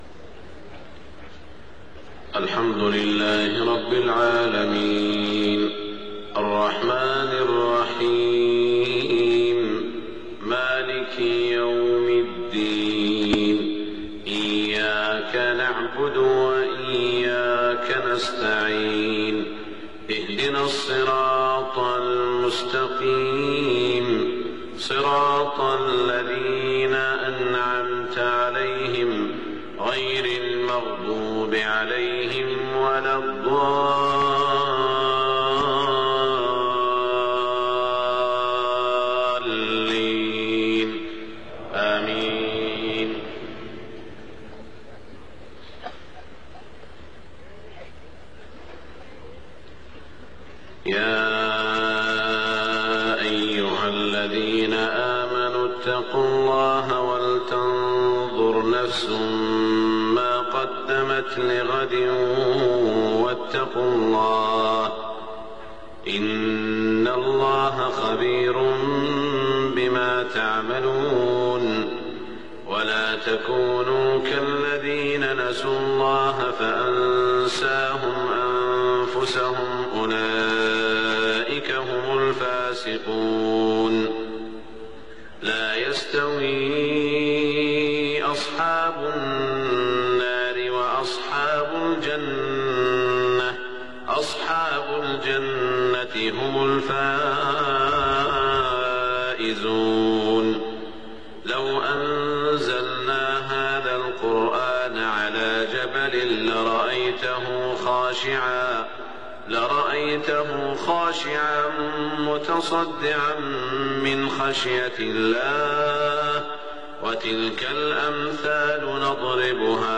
صلاة الفجر 22 ذو الحجة 1427هـ من سورتي الحشر و الصف > 1427 🕋 > الفروض - تلاوات الحرمين